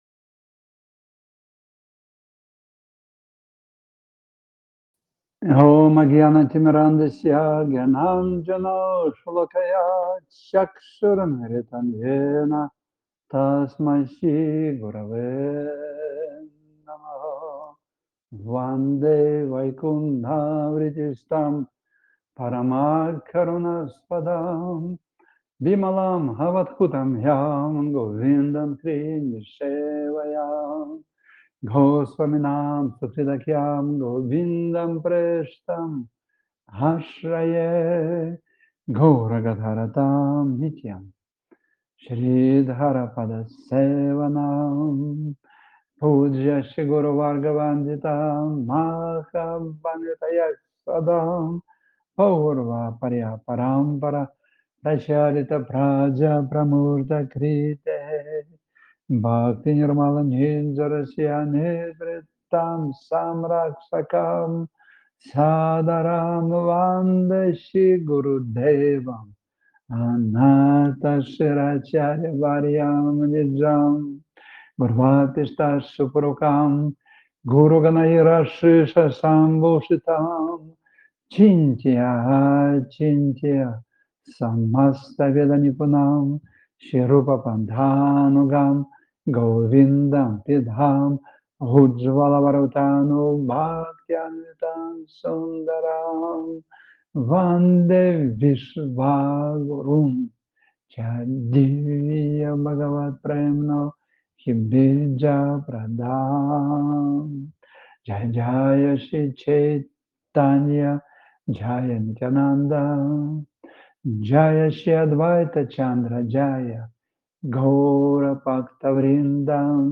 Центр Гупта Говардхан Сева Ашрам на Волге